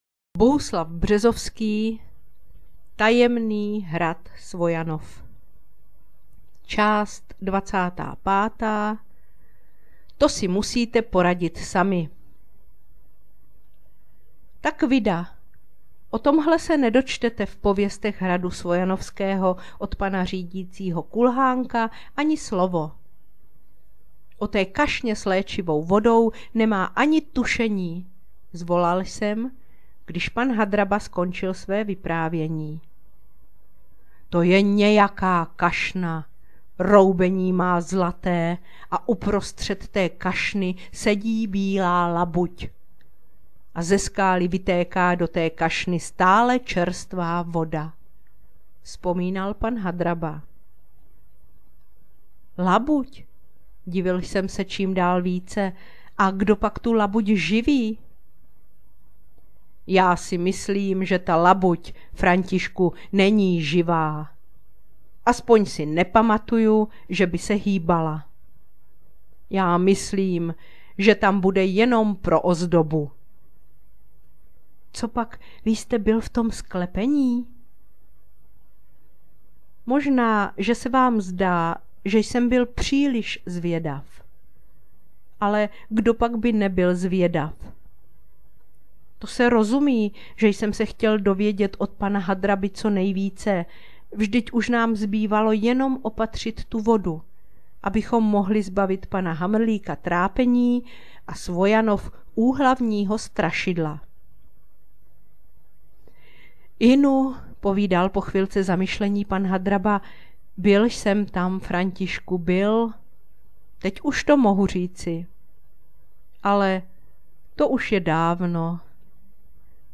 Na příjemně teplé, ale i deštivé jarní dny i večery připravila knihovnice četbu z knihy Bohuslava Březovského Tajemný hrad Svojanov aneb Paměti Františka Povídálka jako takzvanou „knížku do ucha“.